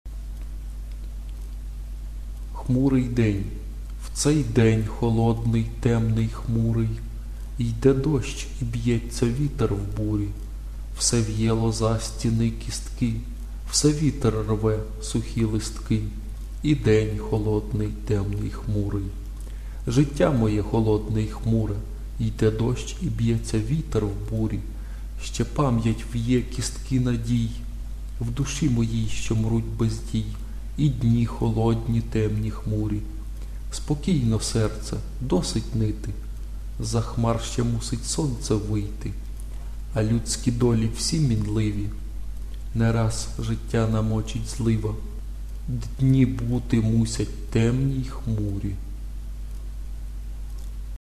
голос спокійний... заворожує 12